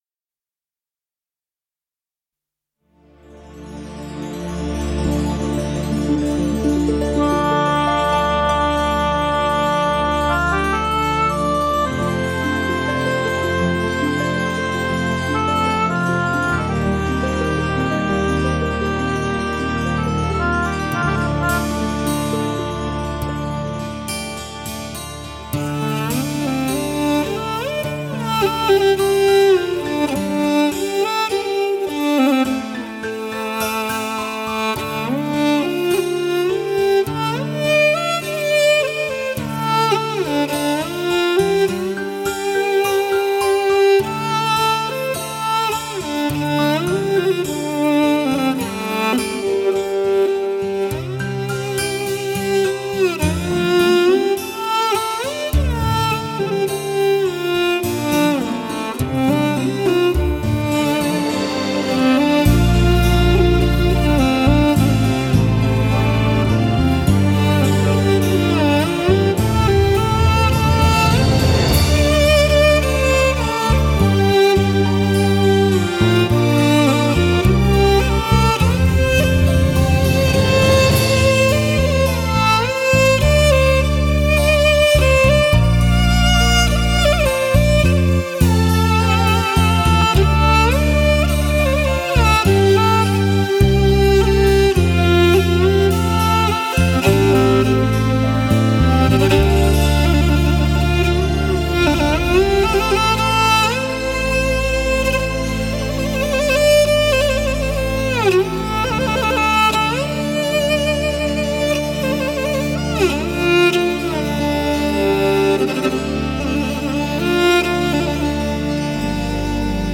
原生态音乐与世界音乐完美结合，最值得收藏的马头琴发烧唱片。
有灵魂，有生命力，有无限的感染力，更有独具一格的豪情。